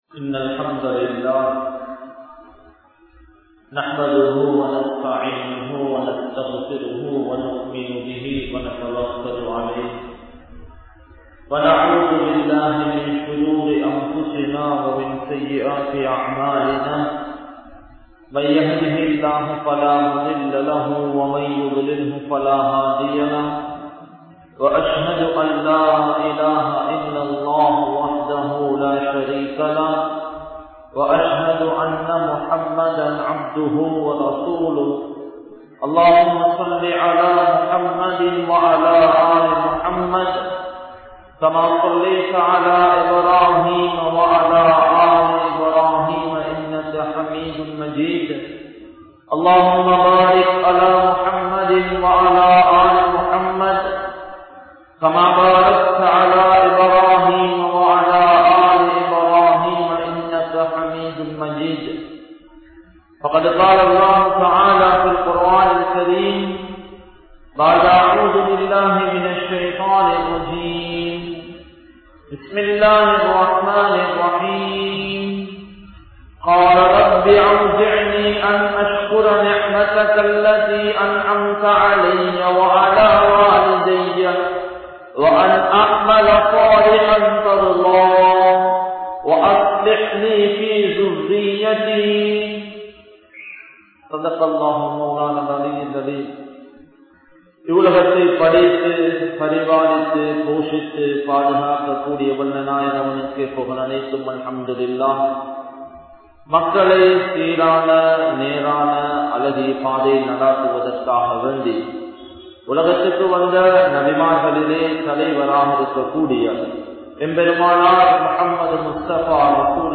Petroarhalum Kulanthaihalin Kalvium (பெற்றோர்களும் குழந்தைகளின் கல்வியும்) | Audio Bayans | All Ceylon Muslim Youth Community | Addalaichenai